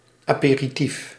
Ääntäminen
Synonyymit borrel Ääntäminen Tuntematon aksentti: IPA: /a.pe.ɾi.ti:f/ Haettu sana löytyi näillä lähdekielillä: hollanti Käännös Ääninäyte Substantiivit 1. apéritif {m} France Paris Luokat Substantiivit